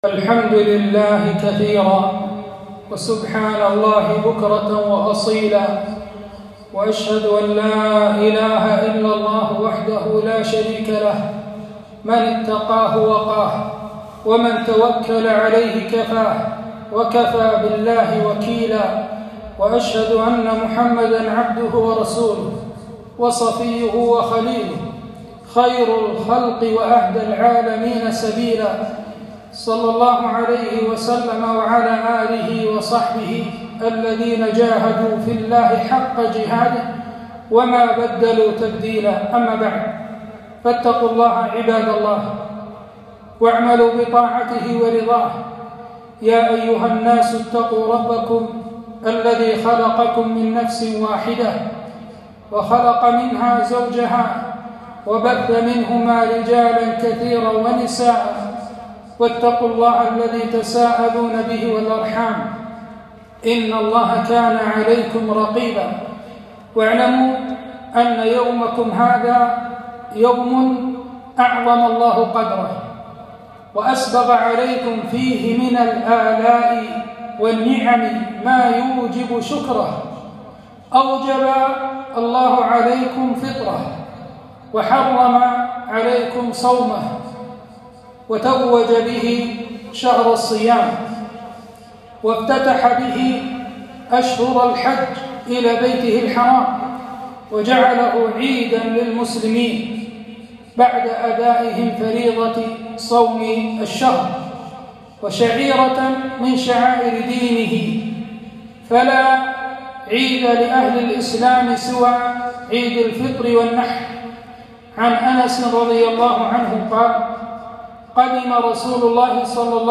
ولتكبروا الله على ماهداكم (خطبة عيد الفطر)